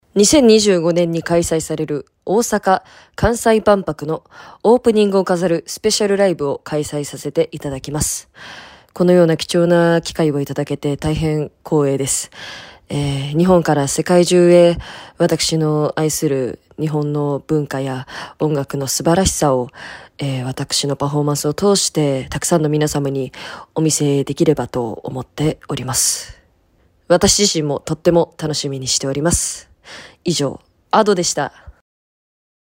■Ado 大阪・関西万博“Ado EXPO OPENING SPECIAL LIVE”コメント（音声でお聴きいただけます）
ado_comment.mp3